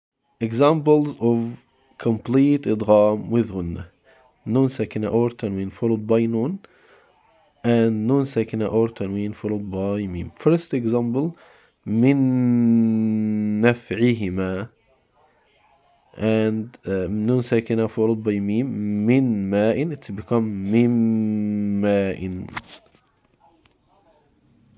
This is a rich nasal resonance that lasts for two beats.
• The Secret of the Sound: While you still hear a nasal sound (Ghunnah), that Ghunnah now belongs to the second letter (the one you are merging into), not the original Noon.
Examples-of-Complete-Idgham-with-Ghunnah.mp3